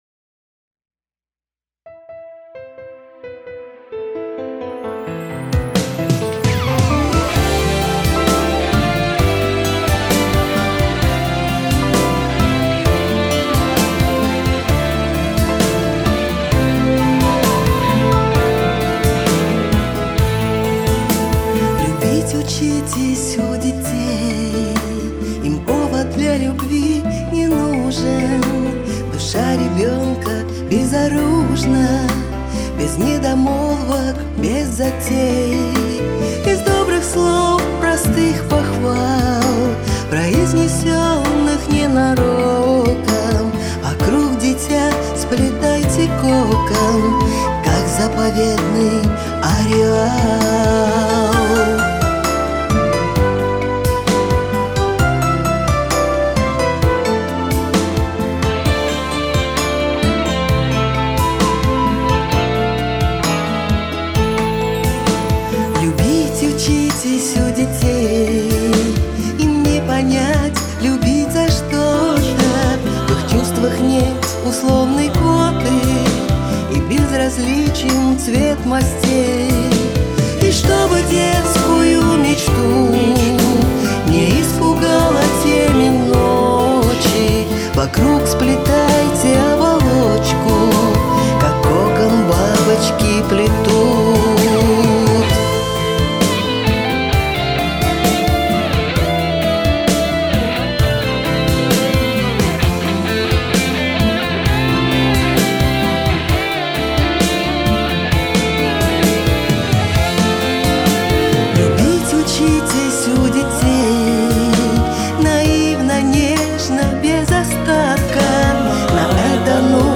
вокал
бэк-вокал